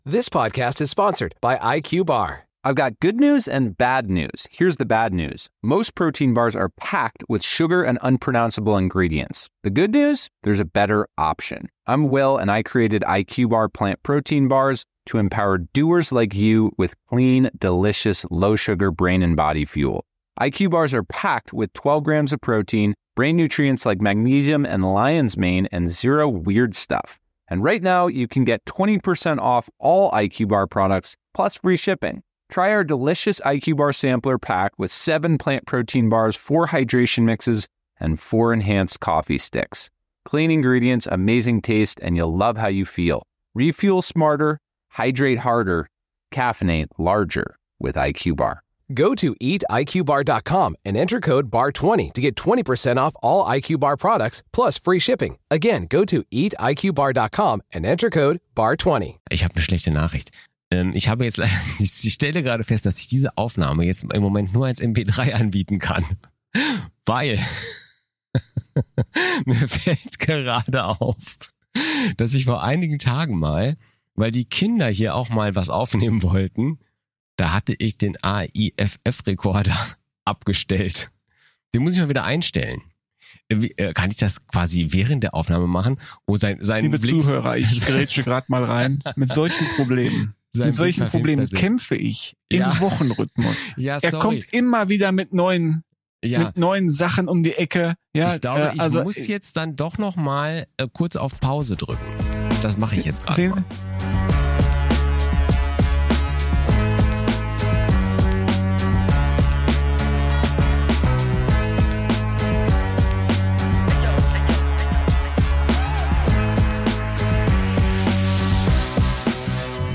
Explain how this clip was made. Über die Audioqualität dieses Podcasts lässt sich ebenso streiten wie über den künstlerischen Wert des Albums, das im Summer of Love 1967 erschienen ist und das als Spielwiese des Mitbegründers einer der erfolgreichsten Bands überhaupt angesehen werden kann....